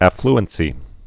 (ăflən-sē, ə-fl-)